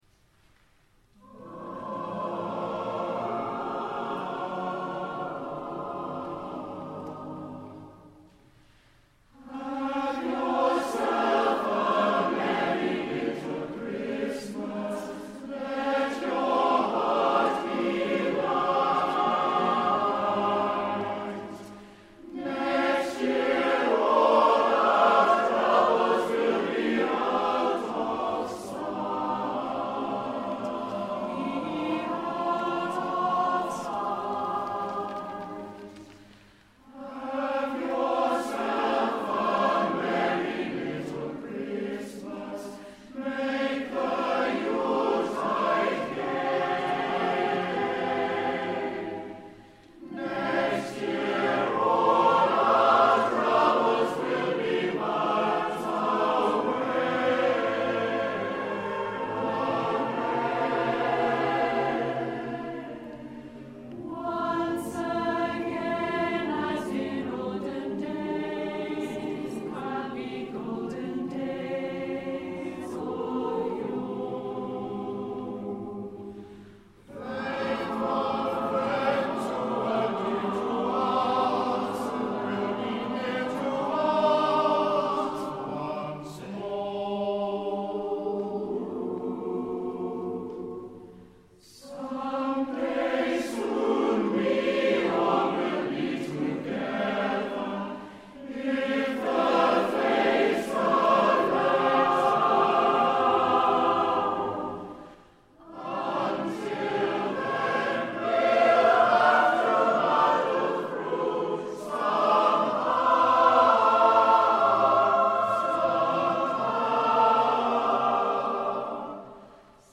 Best wishes for a merry little Christmas (recorded at the Christmas concert back in 2006)!